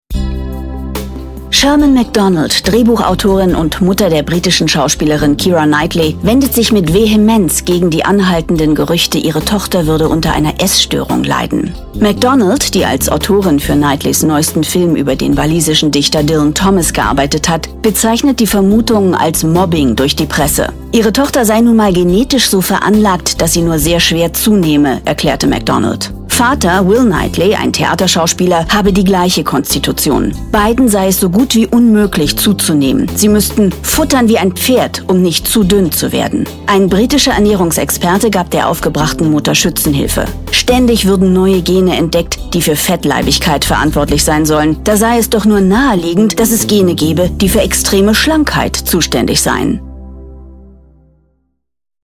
Stimme von Sarah Jessica Parker, Naomi Watts
Irina_von_Bentheim_Doku-.mp3